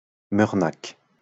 Mœrnach (French pronunciation: [mœʁnak]